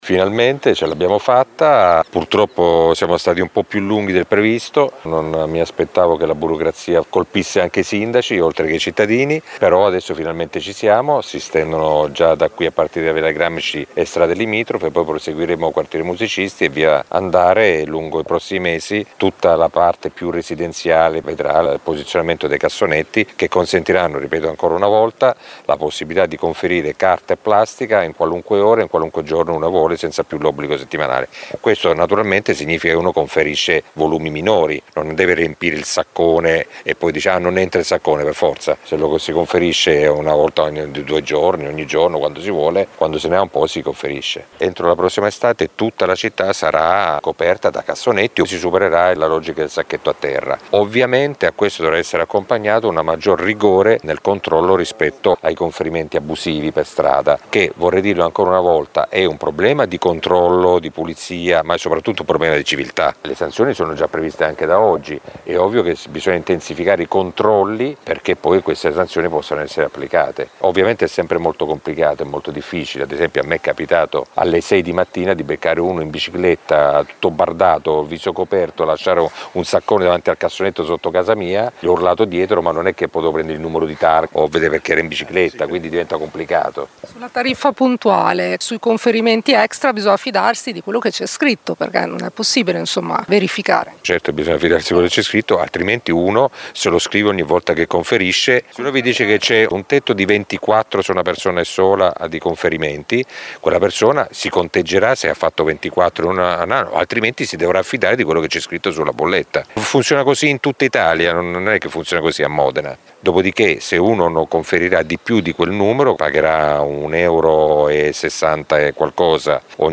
Il sindaco Mezzetti: